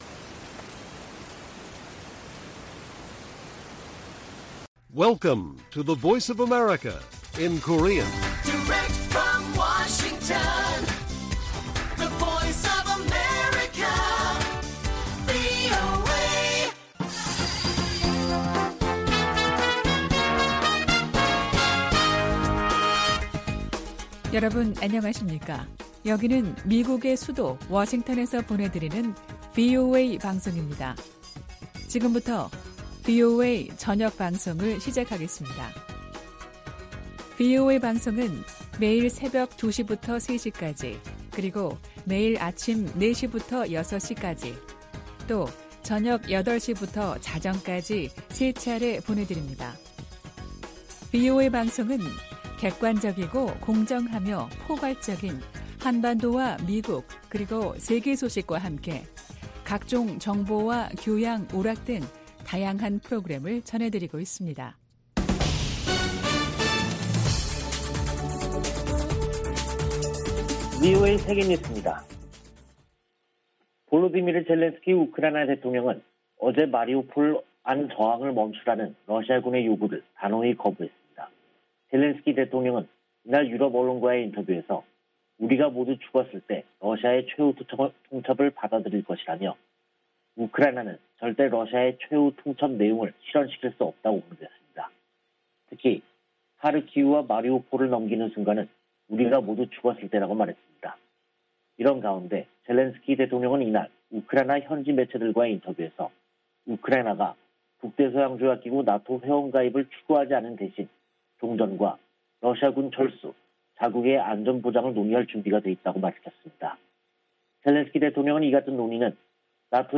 VOA 한국어 간판 뉴스 프로그램 '뉴스 투데이', 2022년 3월 22일 1부 방송입니다. 북한이 연일 미한 연합훈련을 비난하는데 대해, 이는 동맹의 준비태세를 보장하는 주요 방법이라고 미 국방부가 밝혔습니다. 윤석열 한국 대통령 당선인이 북한의 최근 서해상 방사포 발사를 9.19 남북군사합의 위반이라고 말한데 대해 한국 국방부가 합의 위반은 아니라고 밝혀 논란을 빚고 있습니다. 미 의회에서 북한의 사이버 위협에 대응하기 위한 입법 움직임이 활발합니다.